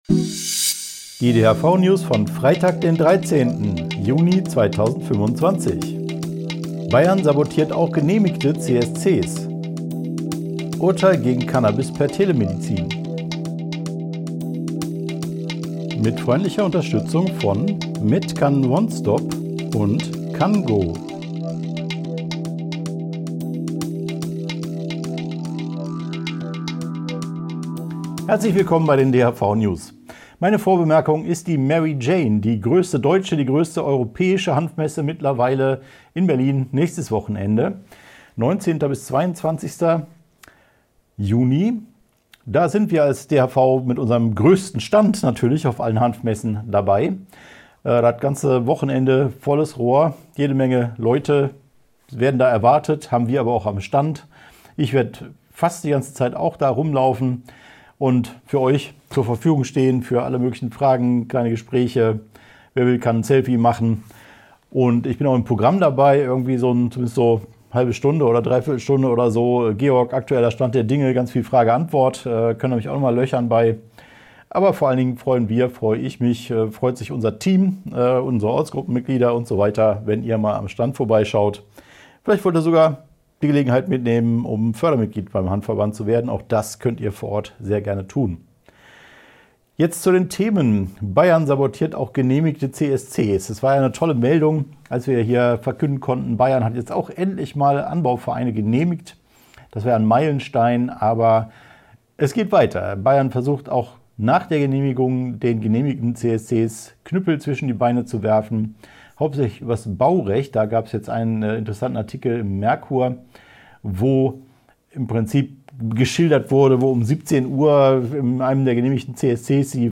DHV-News # 468 Die Hanfverband-Videonews vom 13.06.2025 Die Tonspur der Sendung steht als Audio-Podcast am Ende dieser Nachricht zum downloaden oder direkt hören zur Verfügung.